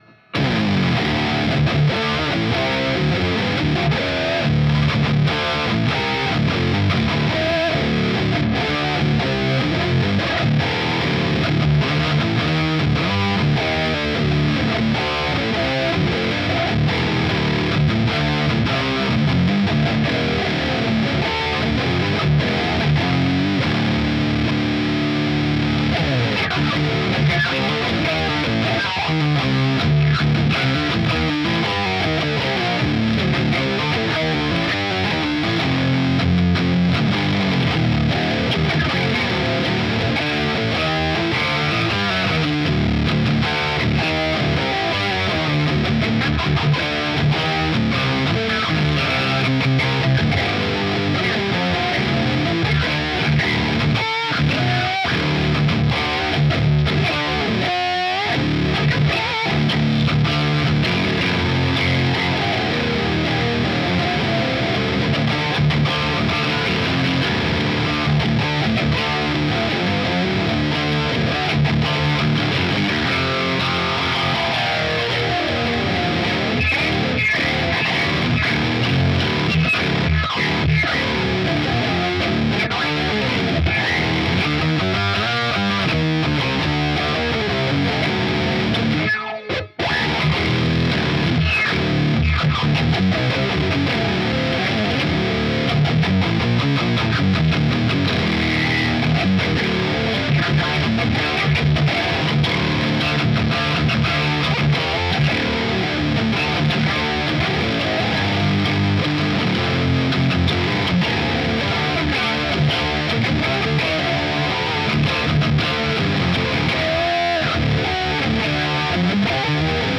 Trying my best to make a MG guitar tone from first principles ...